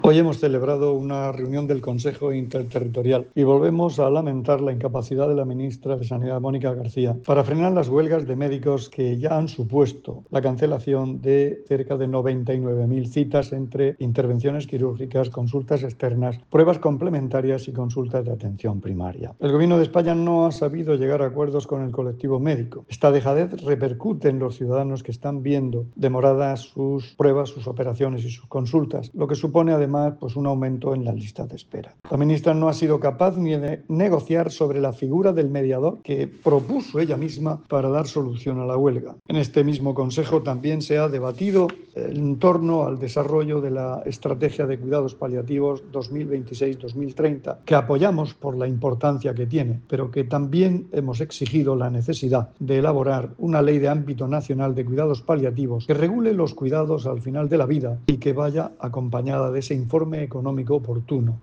Declaraciones del consejero de Salud, Juan José Pedreño, sobre la reunión del Consejo Interterritorial celebrada esta mañana.